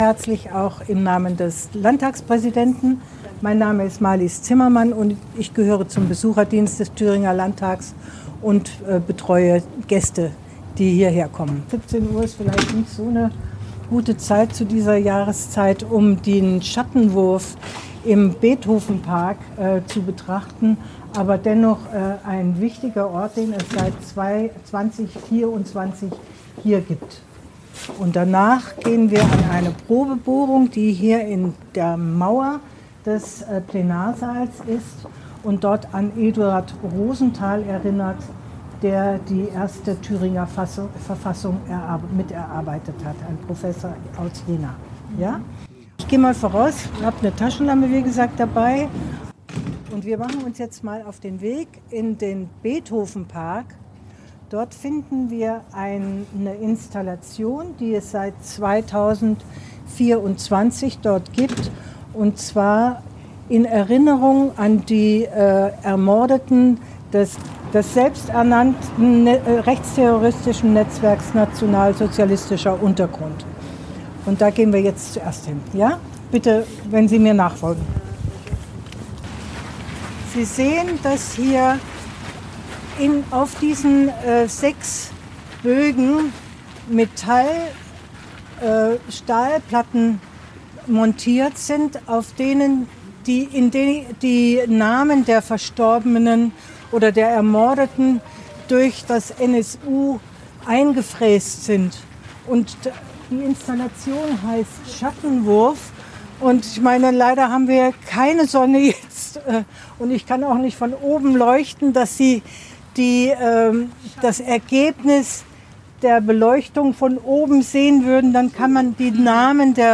Führung zu den Erinnerungsorten am und im Thüringer Landtag